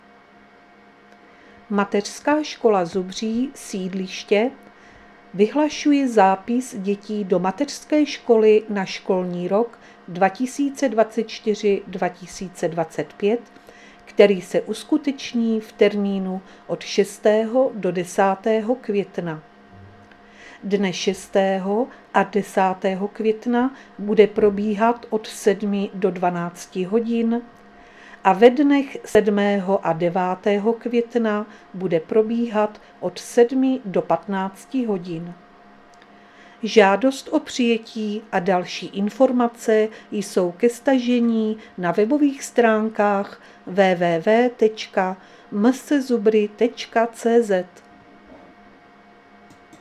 Záznam hlášení místního rozhlasu 30.4.2024
Zařazení: Rozhlas